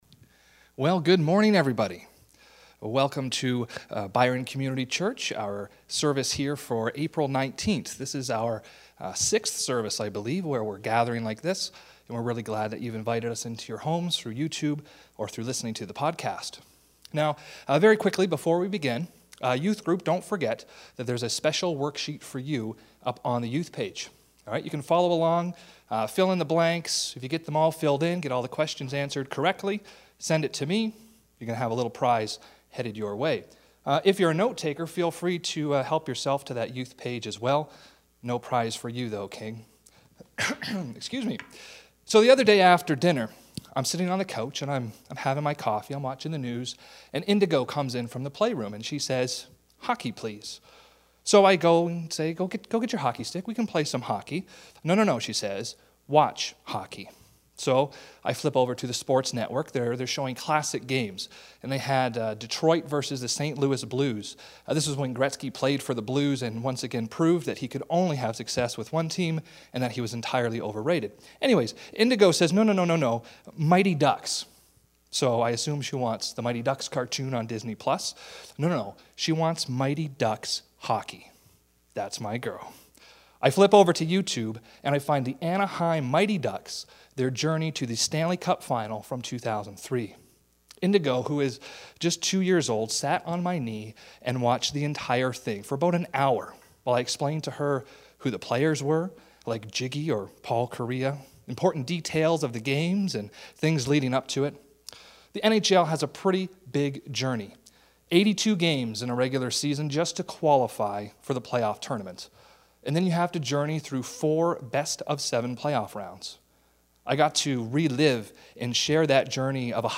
Past Sermons - Byron Community Church